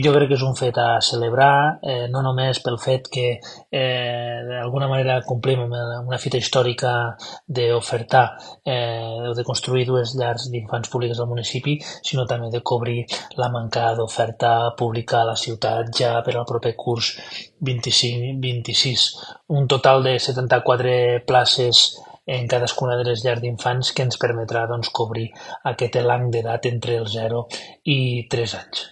El regidor d’educació, Victor Grau, ha explicat que les obres s’iniciaran en breu…